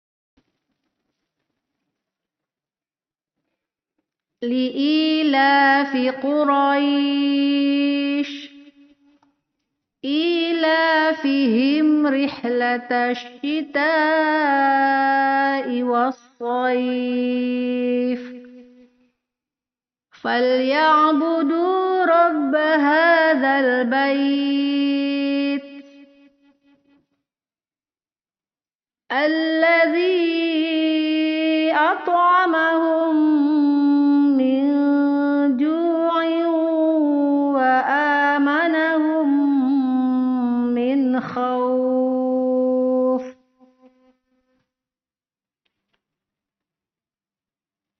Mad ini terjadi pada huruf berbaris Atas (fathah) dan Depan (Dhommah) bertemu dengan huruf ya . bacaan miring (lin) berbunyi : ai, Au. Ini terjadi hanya ketika berhenti.